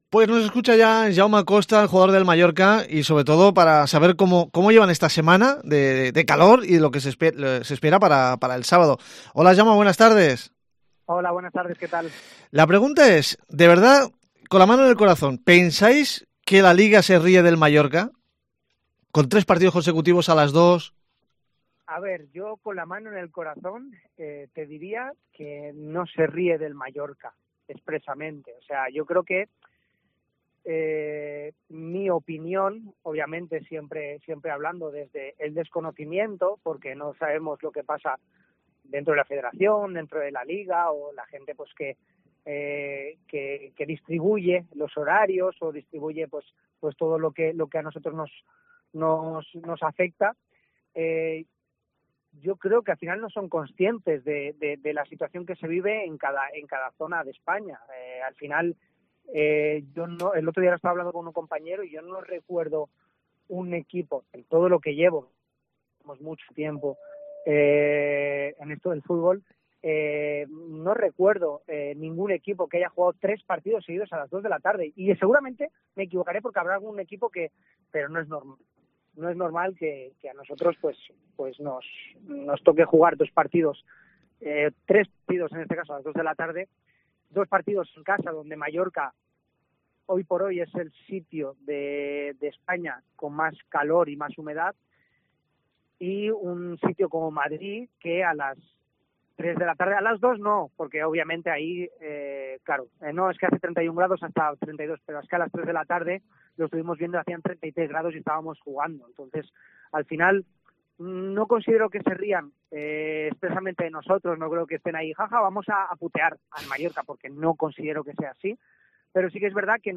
Hablamos con uno de los capitanes del RCD Mallorca, Jaume Costa, del malestar por los horarios que le colocan al Mallorca tras el tercer partido a las 14h y máxime tras el comunicado de la Liga, que le restaba importancia a las condiciones del último partido.